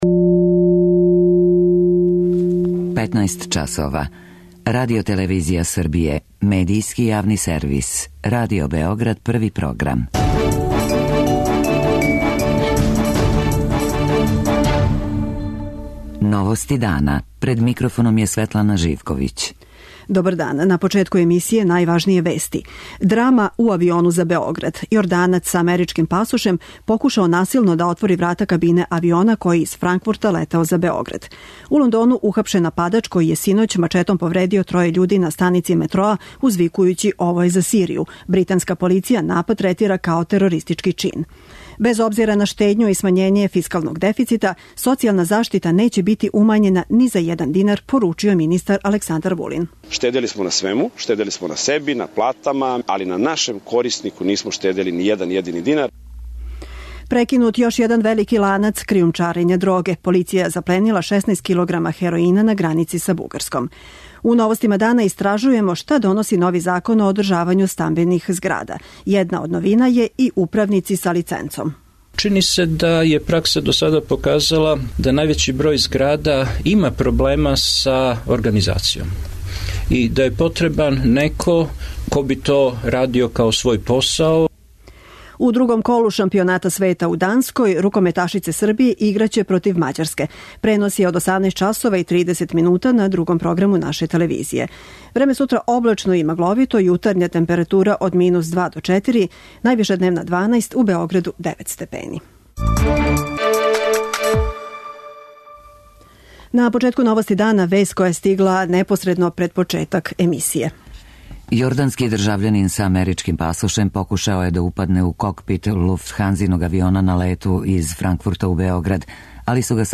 Следећег понедељка, 14. децембра, делегација Србије коју ће предводити премијер Александар Вучић путује у Брисел. О томе, у Новостима дана, разговарамо са Тањом Мишчевић, шефицом преговарачког тима Србије.